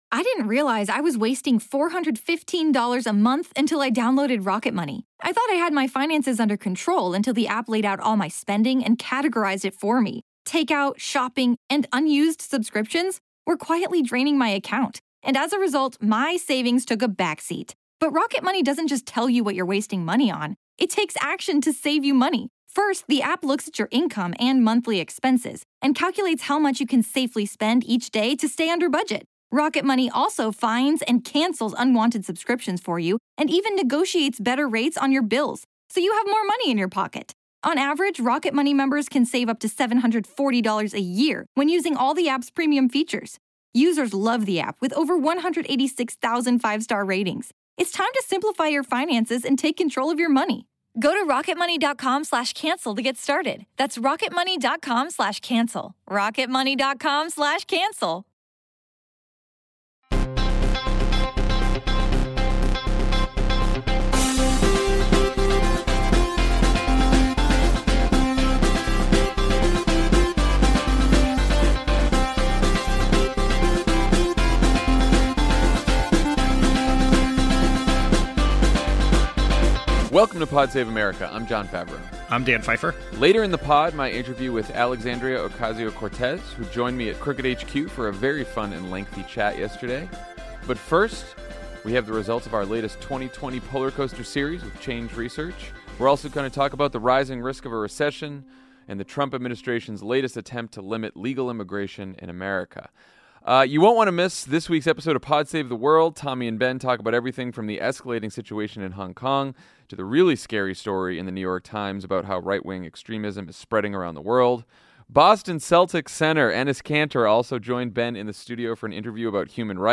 Our new Wisconsin poll shows Trump's strengths and vulnerabilities in a pivotal state, the economy shows warning signs of a possible recession, and the White House shows once again that their re-election strategy involves racist policies. Then Congresswoman Alexandria Ocasio-Cortez talks to Jon about immigration, race, the Green New Deal, and Democratic messaging.